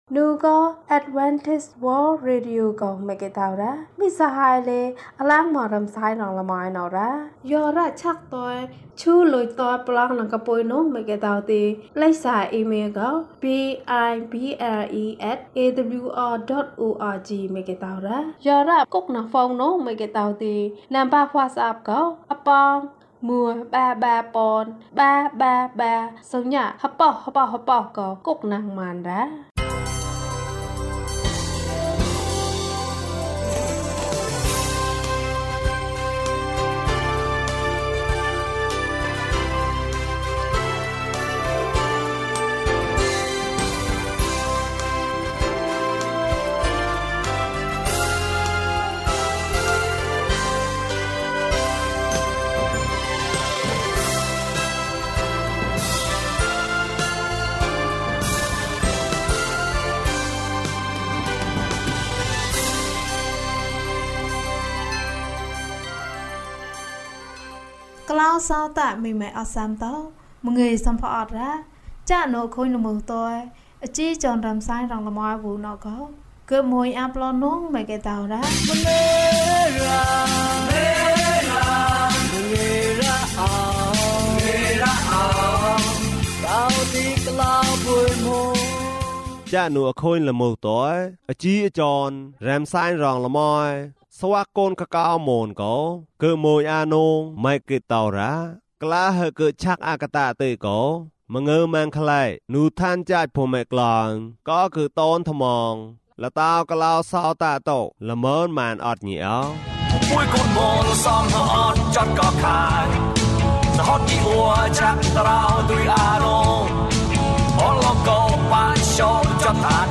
သမ္မာကျမ်းစာ။၀၁ ကျန်းမာခြင်းအကြောင်းအရာ။ ဓမ္မသီချင်း။ တရားဒေသနာ။